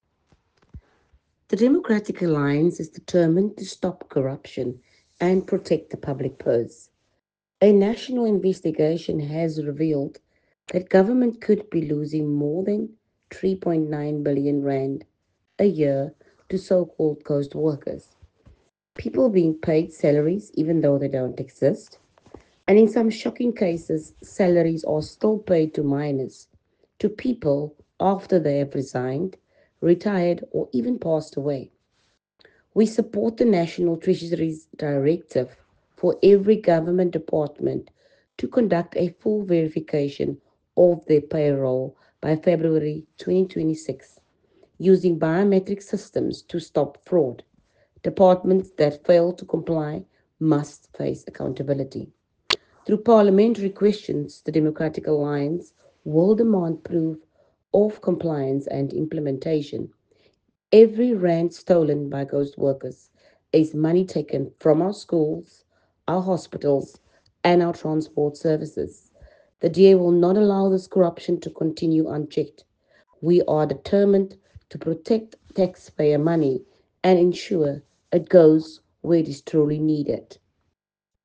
soundbite by Eleanore Bouw Spies MP.